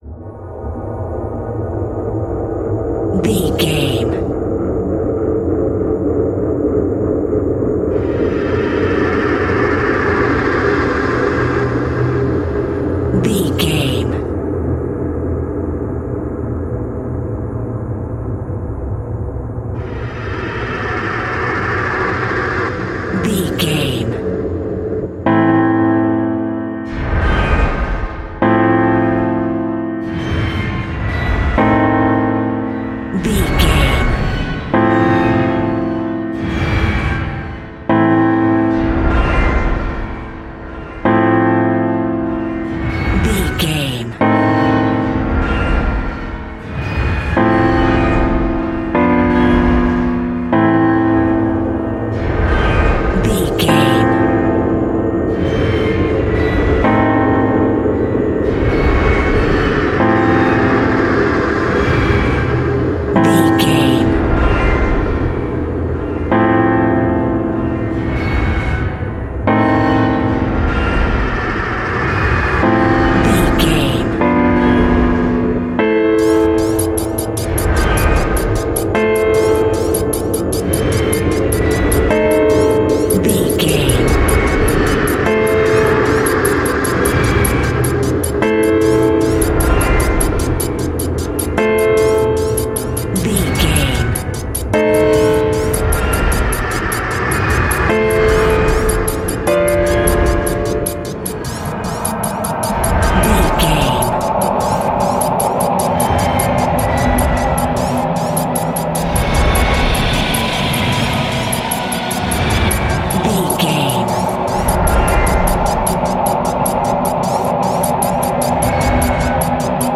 Aeolian/Minor
scary
tension
ominous
dark
suspense
haunting
eerie
synthesizer
horror
keyboards
ambience
pads